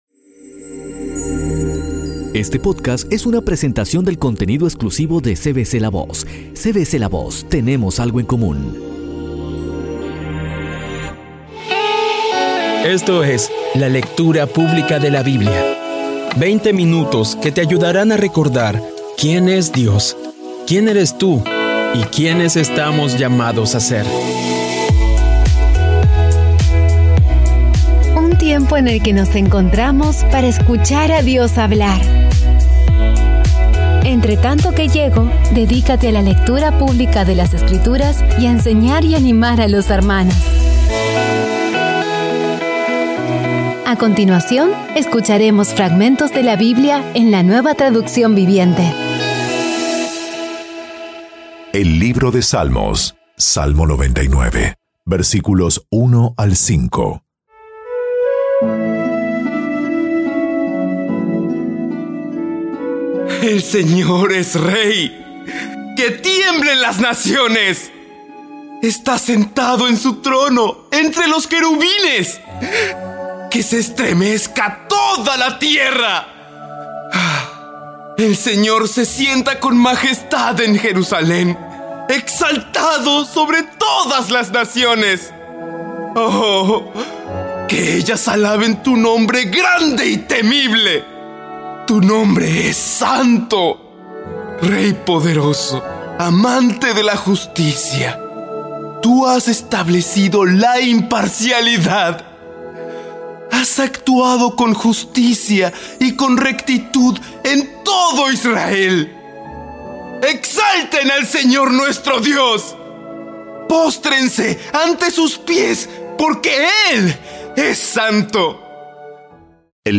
Audio Biblia Dramatizada Episodio 239
Poco a poco y con las maravillosas voces actuadas de los protagonistas vas degustando las palabras de esa guía que Dios nos dio.